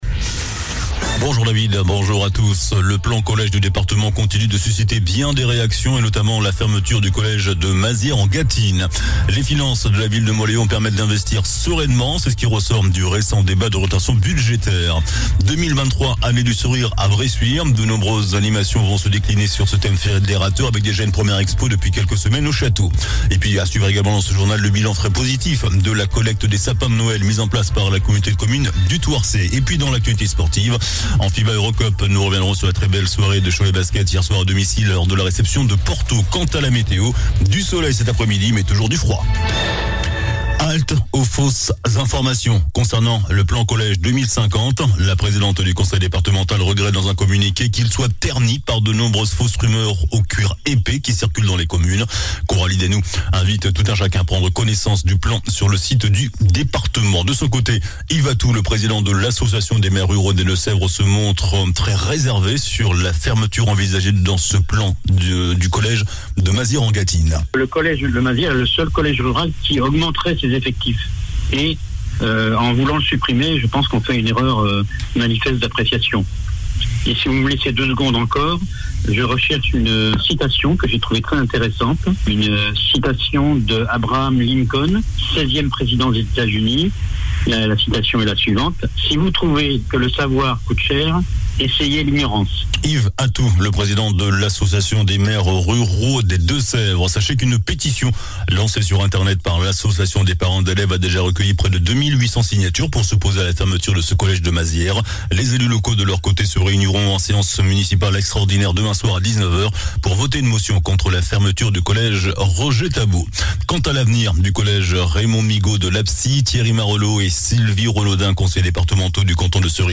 JOURNAL DU JEUDI 09 FEVRIER ( MIDI )